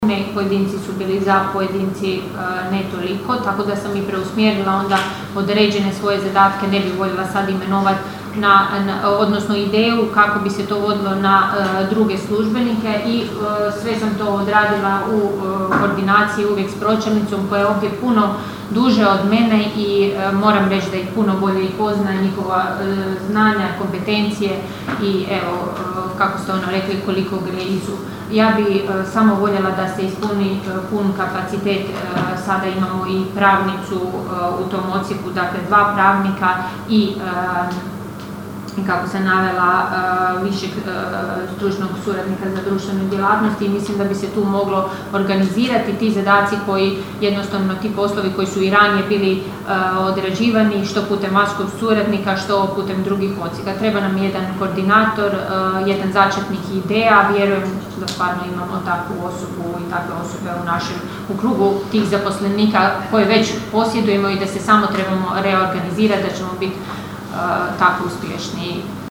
Prijedlog Odluke o II. izmjenama i dopunama Odluke o unutarnjem ustrojstvu i djelokrugu općinske uprave izazvao je žustru raspravu na ovotjednoj sjednici Općinskog vijeća Kršana.
Odgovarajući na pitanje vijećnika Uravića je li razgovarala sa zaposlenicima, načelnica Ana Vuksan kazala je kako je razgovarala: (